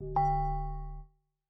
steeltonguedrum_d.ogg